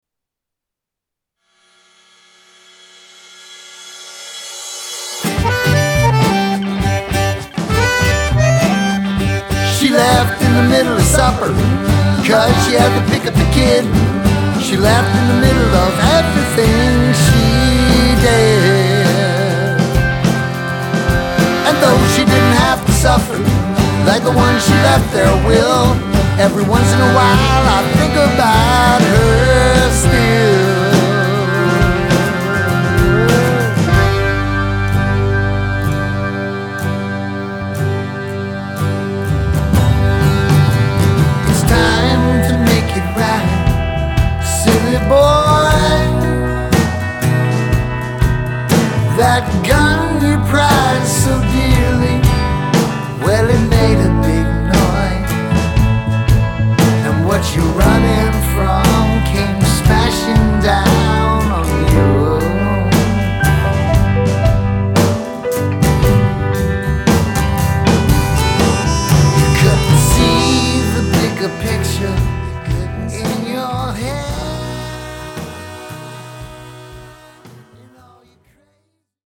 acoustic guitar, drums, vocals
electric guitar, bass, vocals
keyboards, melodica